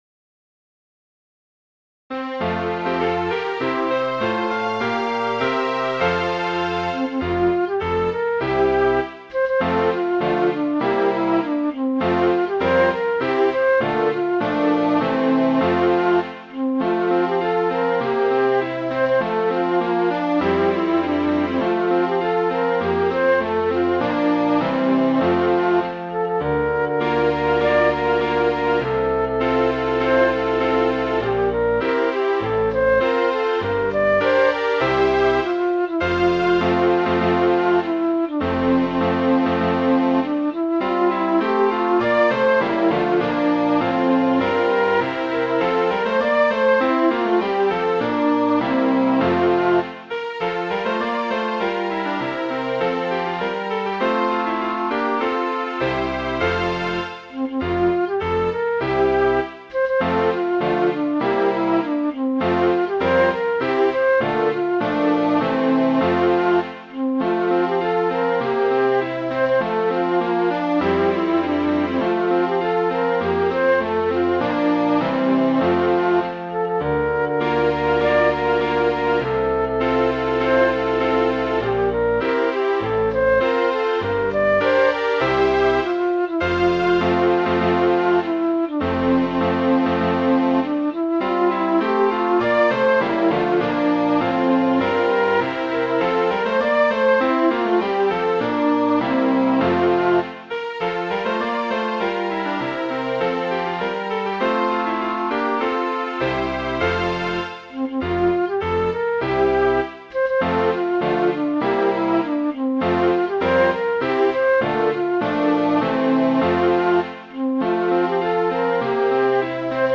Midi File, Lyrics and Information to Tom Tough